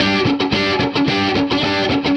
Index of /musicradar/80s-heat-samples/110bpm
AM_HeroGuitar_110-G02.wav